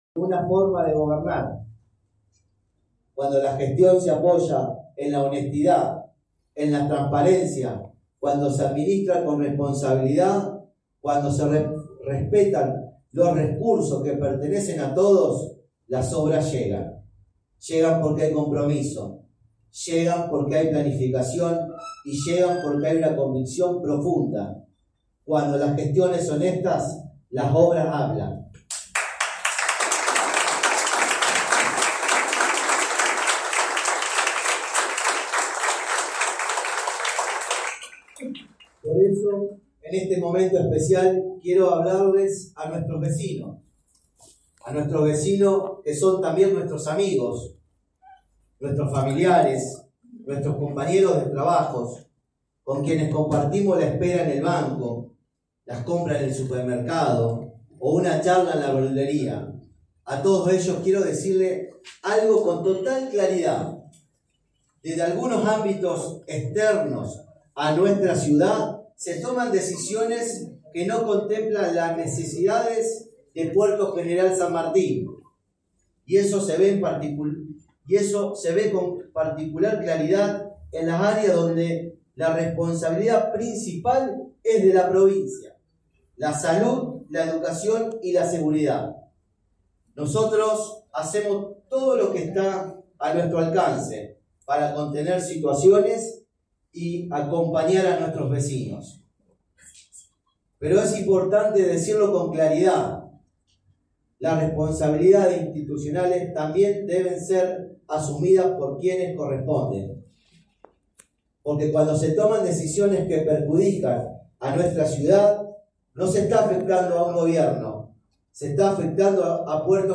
El concejal Juan Manuel De Grandis, presidente del Concejo Deliberante de Puerto General San Martín, encabezó este viernes la apertura del 37º período de sesiones ordinarias del cuerpo legislativo.